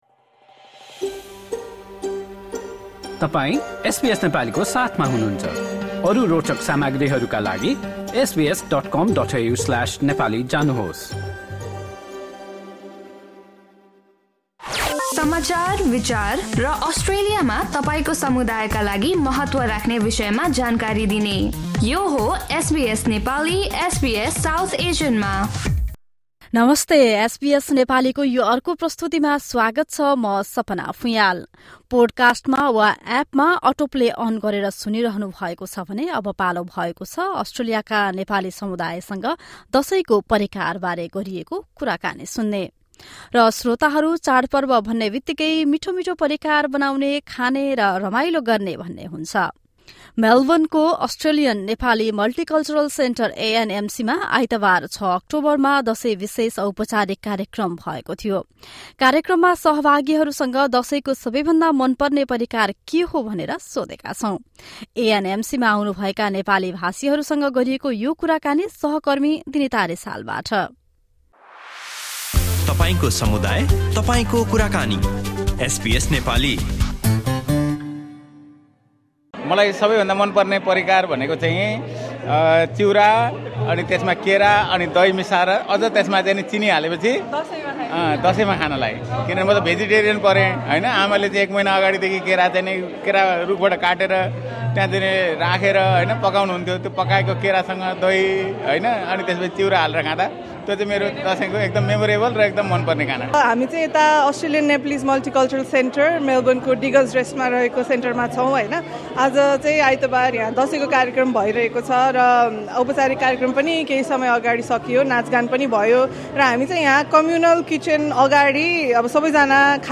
A special Dashain event was held on Sunday 6 October at the Australian Nepali Multicultural Center (ANMC) in Melbourne. SBS Nepali spoke to some of the Nepali speakers participating in the program about their favorite dishes during Dashain.